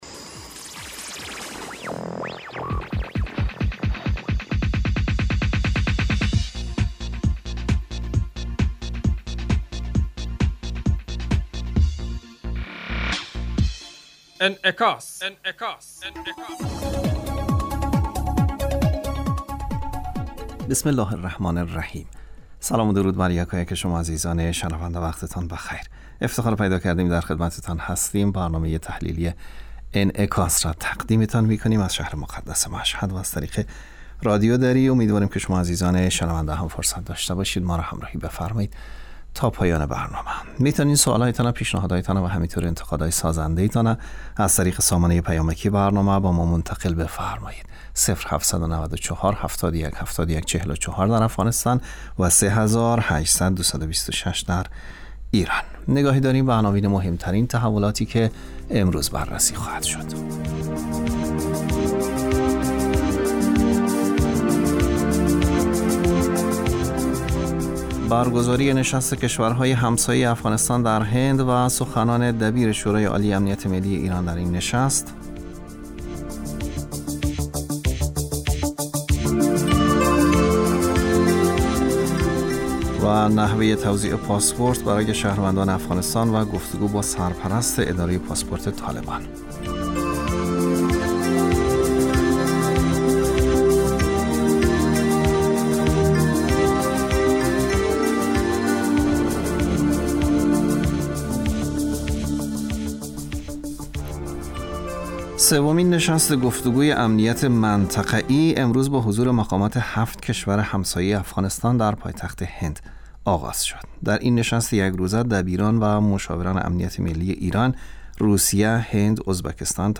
برگزاری نشست کشورهای همسایه افغانستان در هند و سخنان دبیر شورای عالی امنیت ملی ایران در این نشست. نحوه توزیع پاسپورت برای شهروندان افغانستان و گفت و گو با سرپرست اداره پاسپورت طالبان. برنامه انعکاس به مدت 30 دقیقه هر روز در ساعت 12:00 ظهر (به وقت افغانستان) بصورت زنده پخش می شود. این برنامه به انعکاس رویدادهای سیاسی، فرهنگی، اقتصادی و اجتماعی مربوط به افغانستان و تحلیل این رویدادها می پردازد.